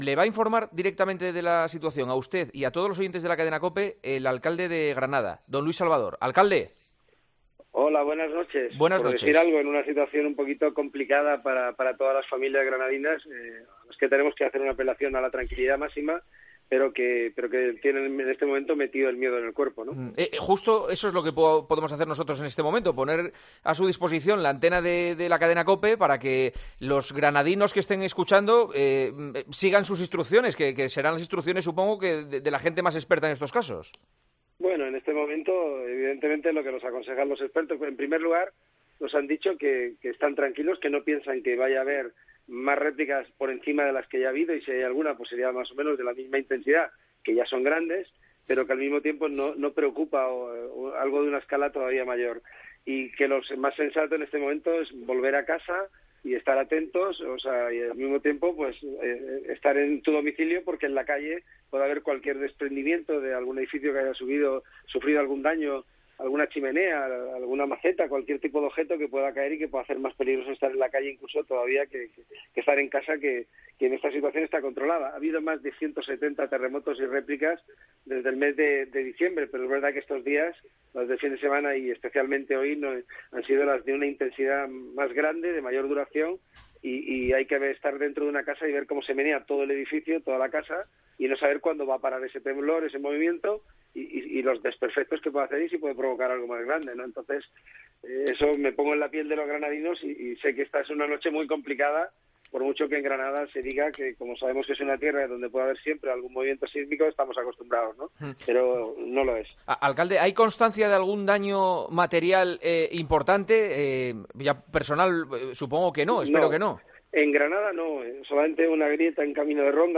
AUDIO: Hablamos con el alcalde de Granada sobre la serie de terremotos que ha sufrido la localidad esta noche.